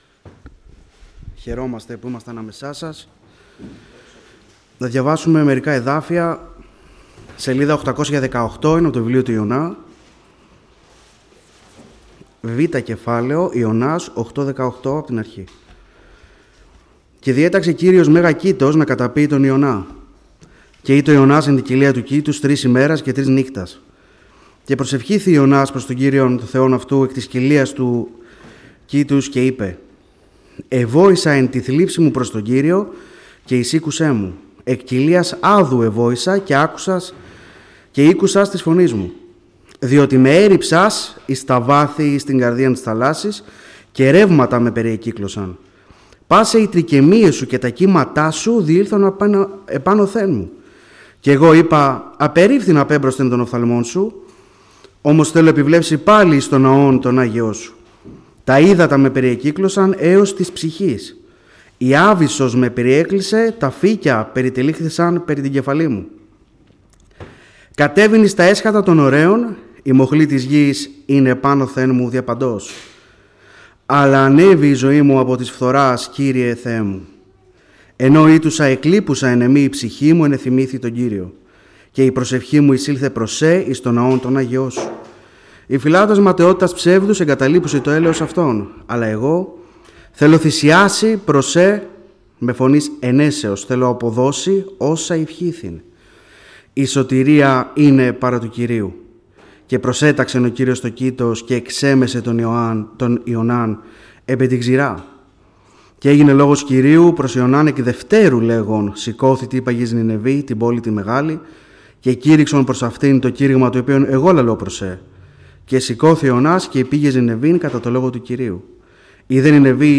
Series: Κήρυγμα Ευαγγελίου